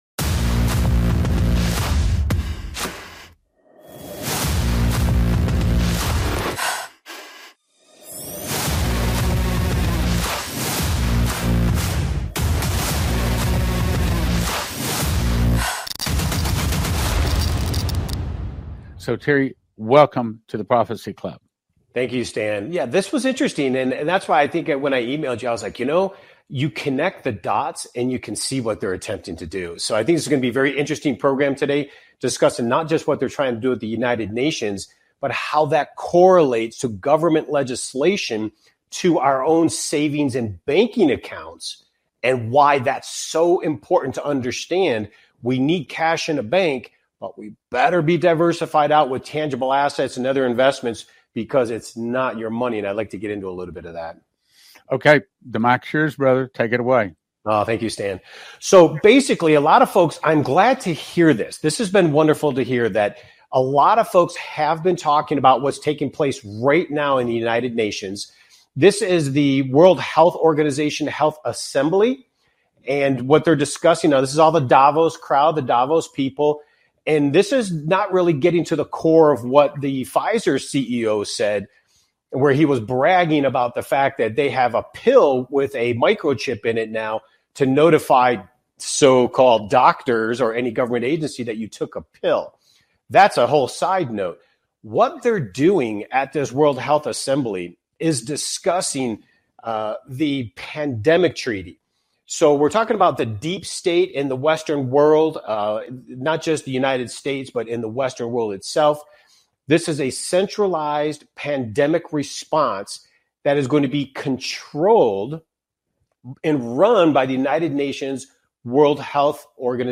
Talk Show Episode, Audio Podcast, Rigged Against You and The New Micro Chip PILL and Inflation on , show guests , about The New Micro Chip PILL and Inflation, categorized as Business,Investing and Finance,History,News,Politics & Government,Society and Culture,Technology